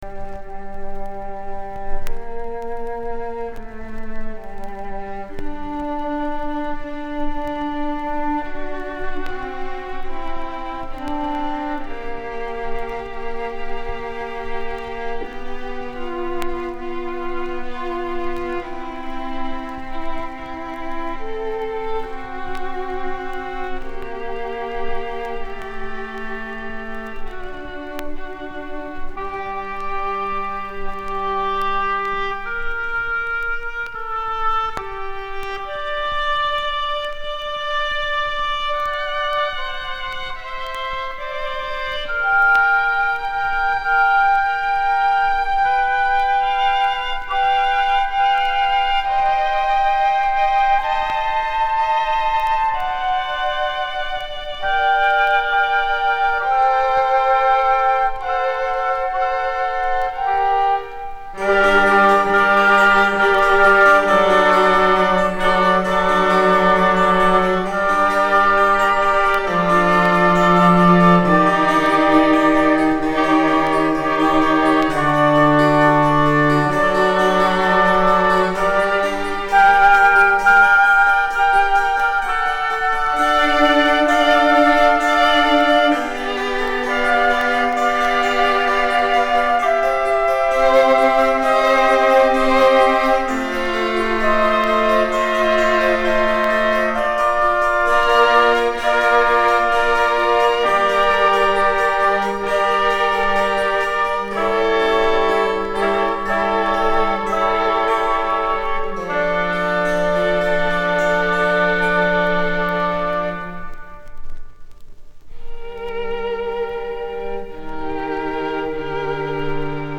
М БЕРЕЗОВСКИЙ_КОНЦЕРТ ДЛЯ 4 ИНСТРУМЕНТОВ И КЛАВЕСИНА(1766).mp3